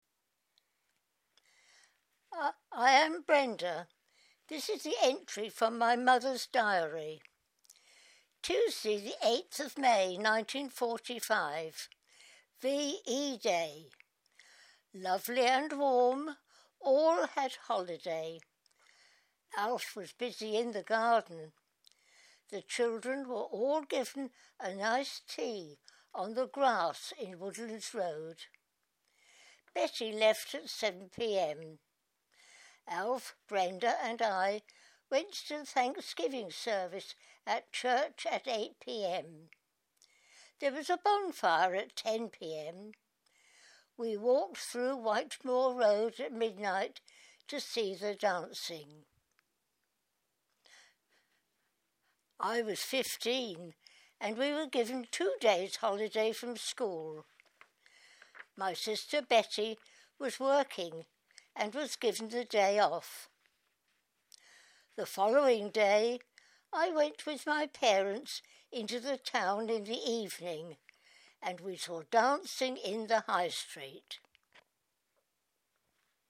speaking of her own memories.